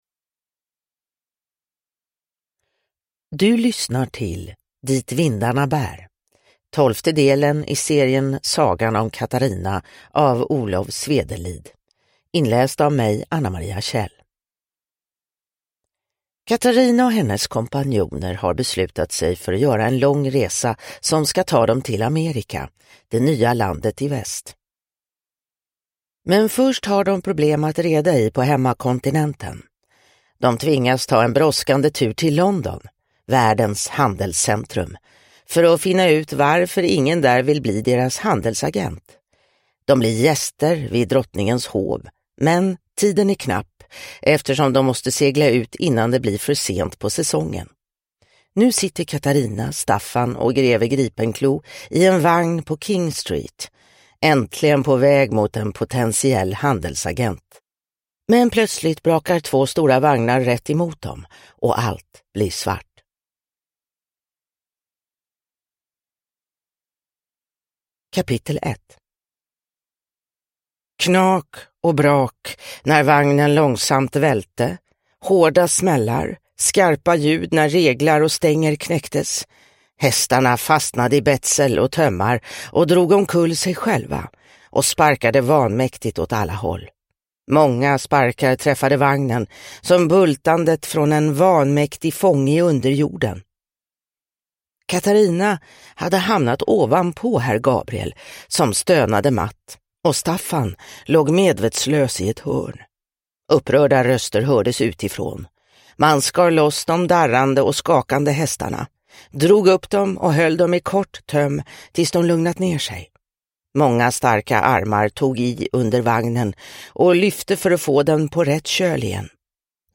Dit vindarna bär – Ljudbok – Laddas ner